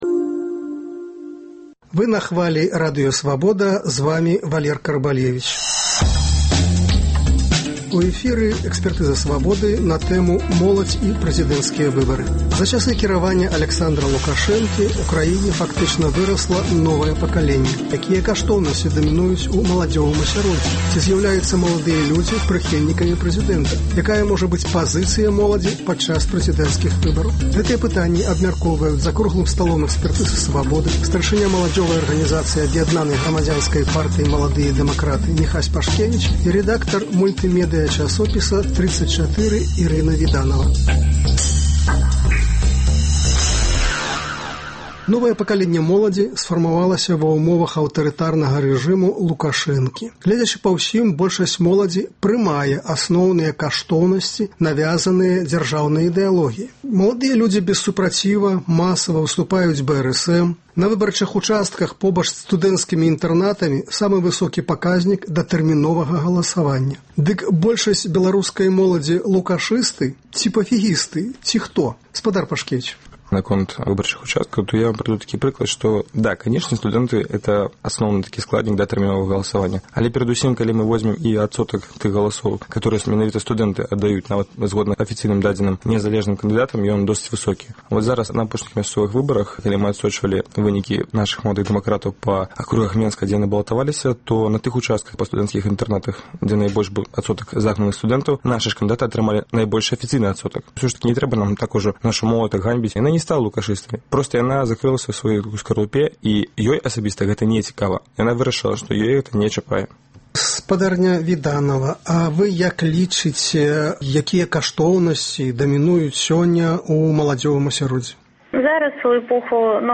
Гэтыя пытаньні абмяркоўваюць за круглым сталом